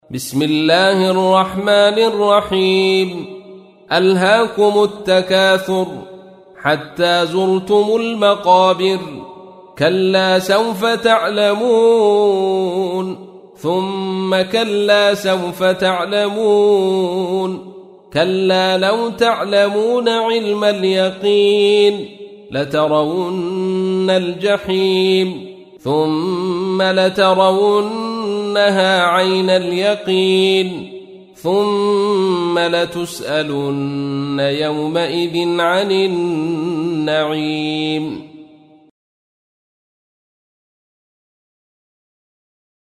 تحميل : 102. سورة التكاثر / القارئ عبد الرشيد صوفي / القرآن الكريم / موقع يا حسين